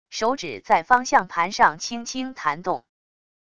手指在方向盘上轻轻弹动wav音频